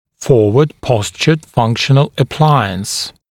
[‘fɔːwəd ‘pɔsʧəd ‘fʌŋkʃ(ə)n(ə)l ə’plaɪəns][‘фо:уэд ‘посчэд ‘фанкш(э)н(э)л э’плайэнс]функциональный аппарат, позиционирующий нижнюю челюсть вперед